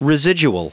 Prononciation du mot residual en anglais (fichier audio)
Prononciation du mot : residual